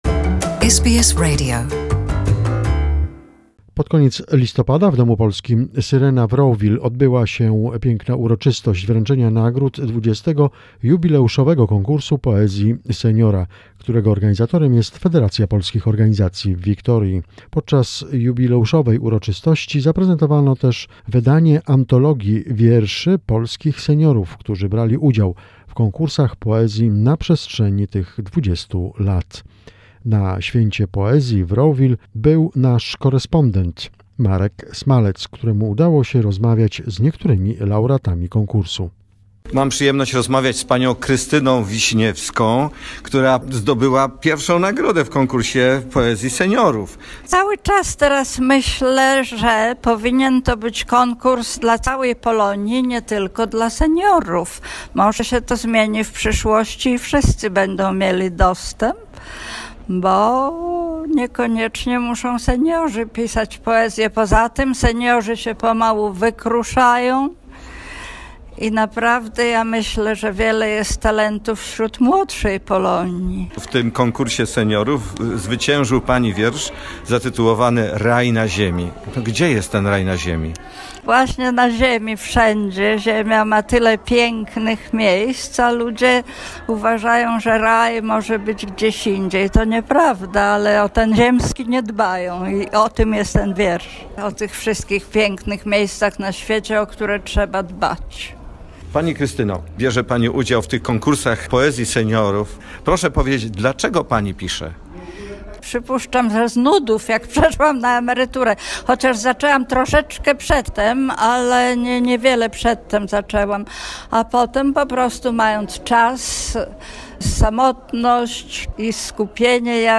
On Wednesday, November 21 at the Polish Club 'Syrena' in Rowville, a ceremony of awarding the 20th Senior Poetry Competition was held, organized by the Federation of Polish Community Council in Victoria.